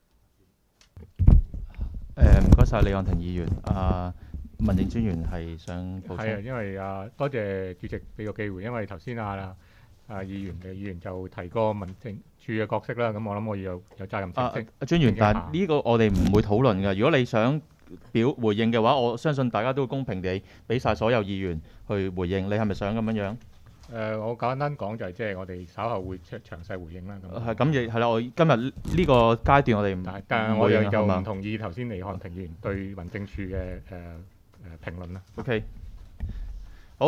区议会大会的录音记录
深水埗区议会会议室